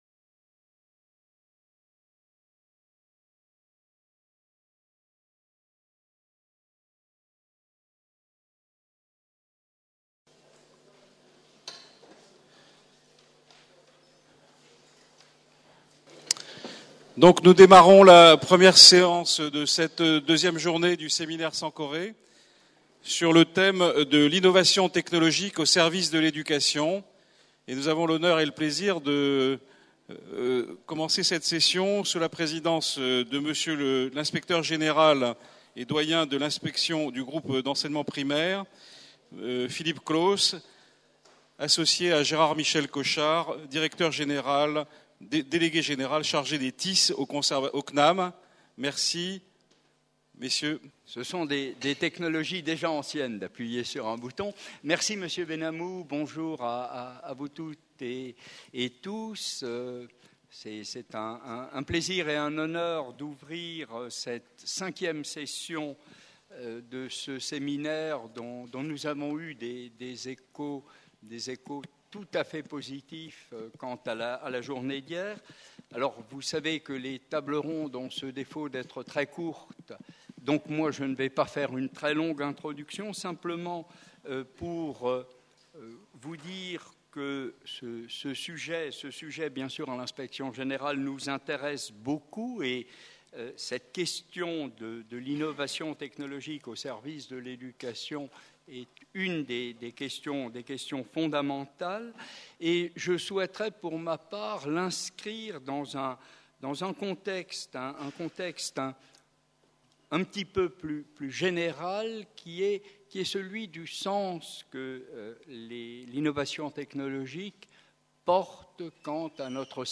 PREMIER SÉMINAIRE INTERNATIONAL SANKORÉ DE RECHERCHE UNIVERSITAIRE SUR LA PÉDAGOGIE NUMÉRIQUE
Table ronde